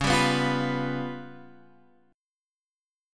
Db7b5 chord